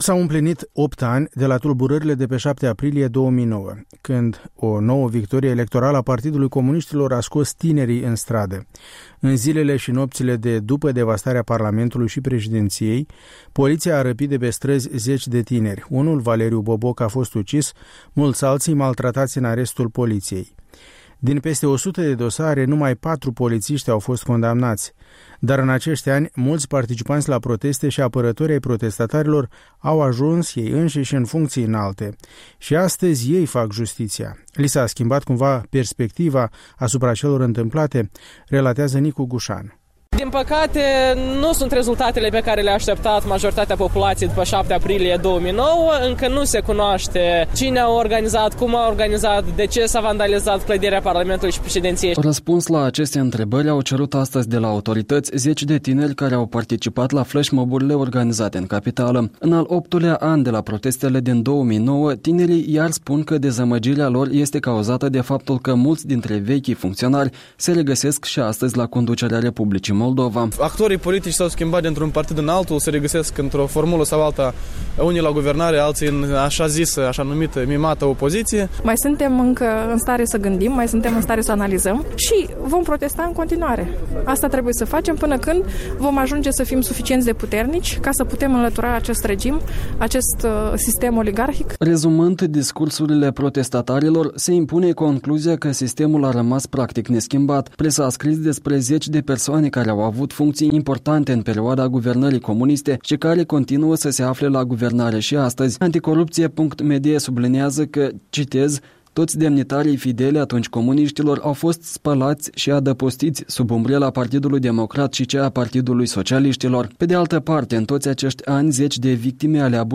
Câteva opinii ale celor cu care am vorbit la flashmob-urile organizate astăzi în capitală: